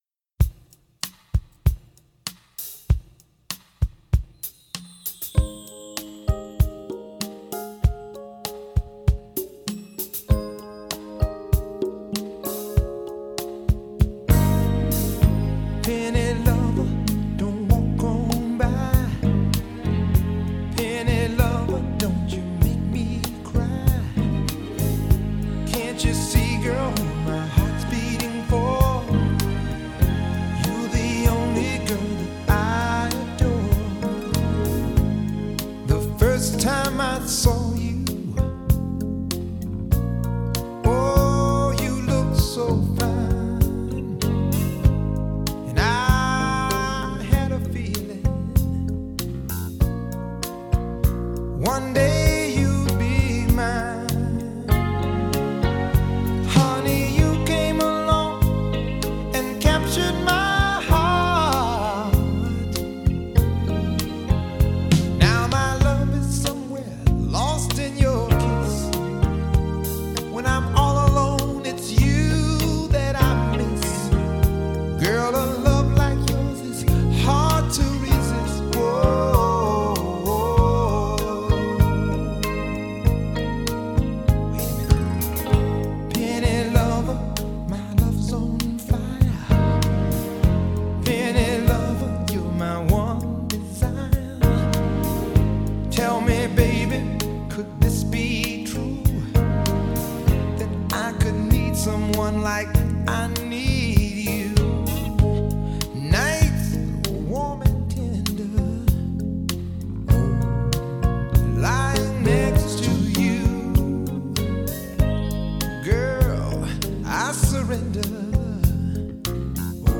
Genre: Pop
Bass Guitar
Electric Piano [Fender Rhodes]
Synthesizer [GS1]